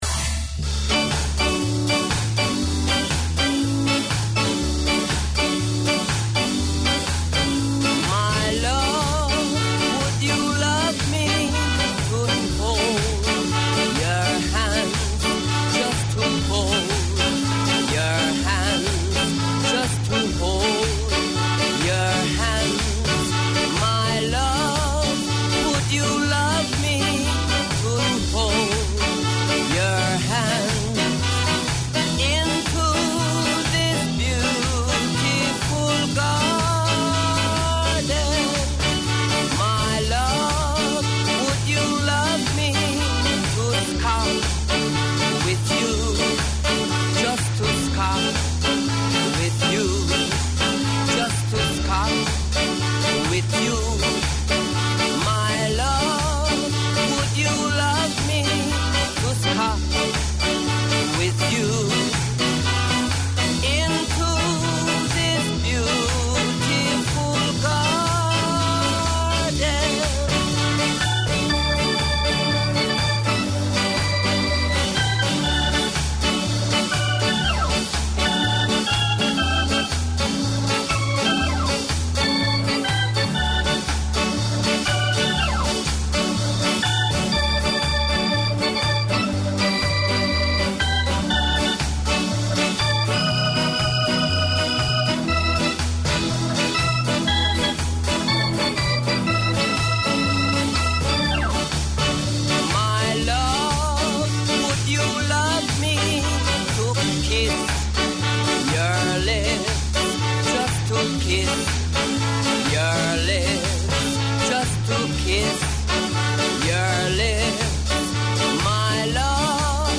The Filipino Show 12:40pm WEDNESDAY Community magazine Language